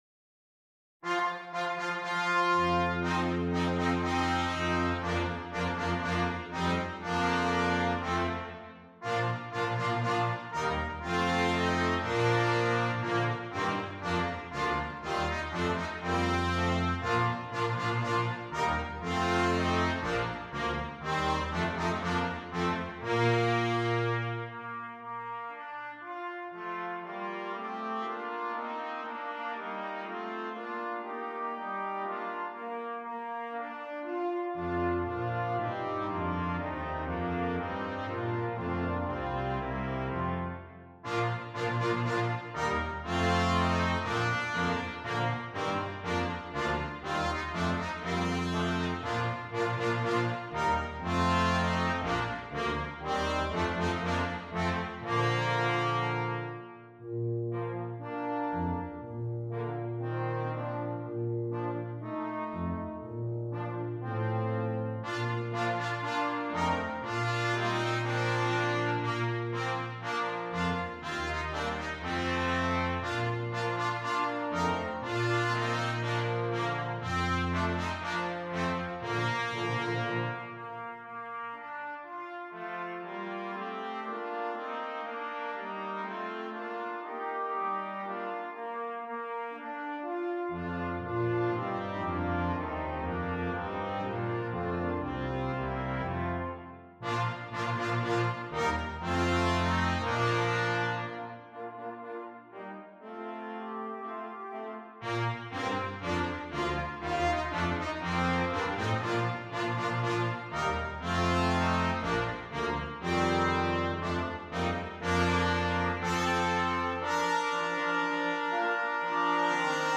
Brass Quintet
Canadian Folk Song